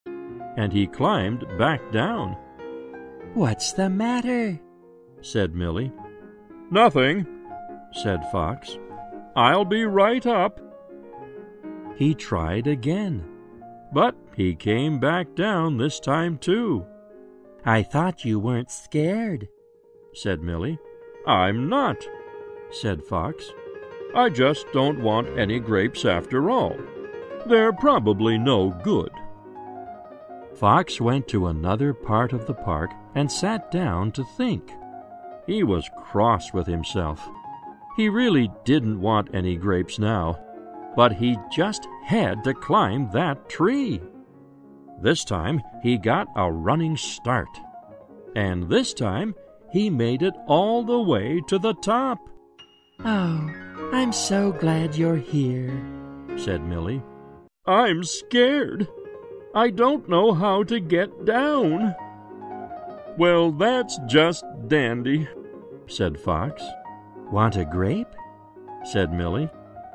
在线英语听力室小狐外传 第48期:树顶的听力文件下载,《小狐外传》是双语有声读物下面的子栏目，非常适合英语学习爱好者进行细心品读。故事内容讲述了一个小男生在学校、家庭里的各种角色转换以及生活中的趣事。